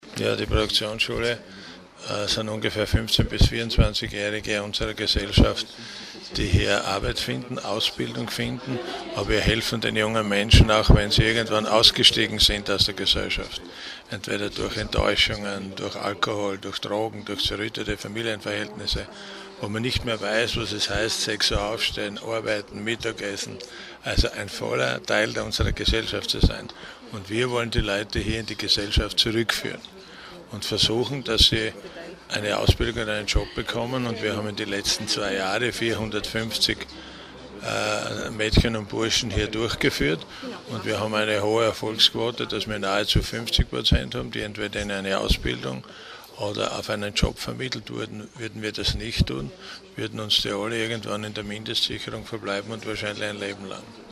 Zwei Jahre steirische Produktionsschulen - O-Töne
Graz (10. Juli 2012).-  Heute (10.07.2012) luden Bundesminister Rudolf Hundstorfer und LH-Stellvertreter Siegfried Schrittwieser anlässlich des zweijährigen Bestehens der Produktionsschulen in der Steiermark zu einer Pressekonferenz.
Soziallandesrat Siegfried Schrittwieser: